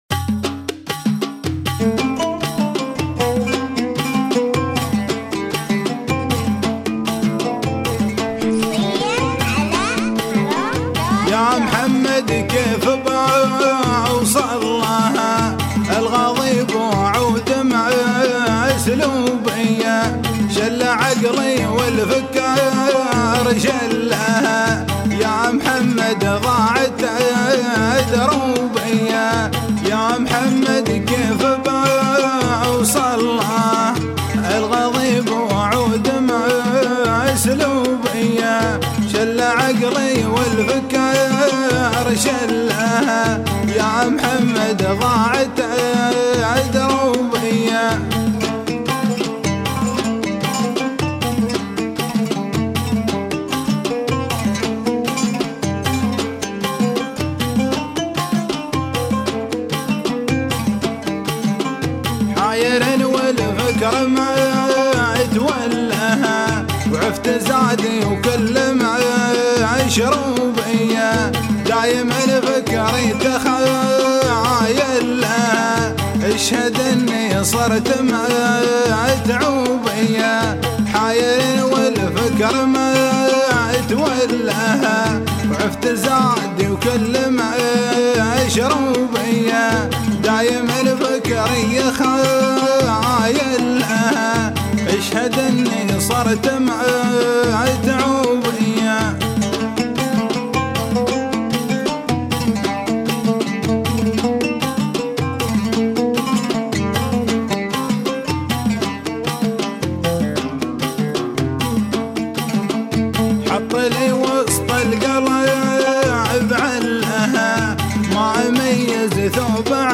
اغاني اماراتيه